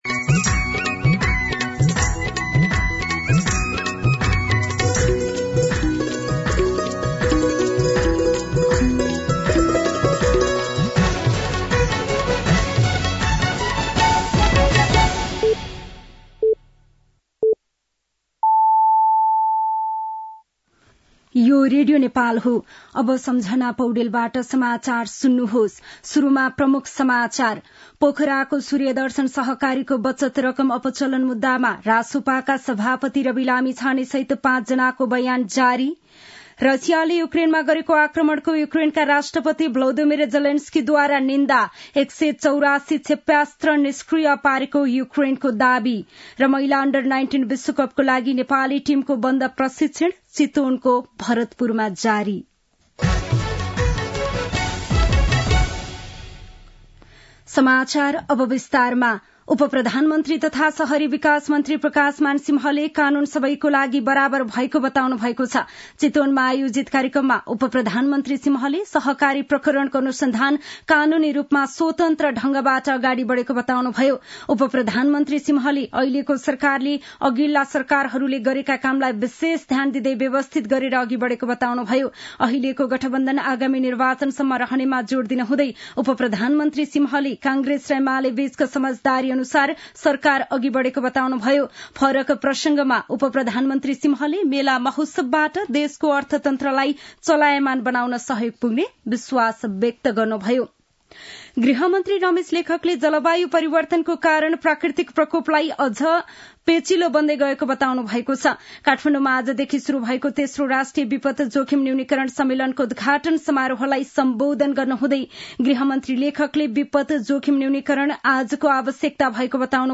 दिउँसो ३ बजेको नेपाली समाचार : १२ पुष , २०८१
3-pm-Nepali-News-3.mp3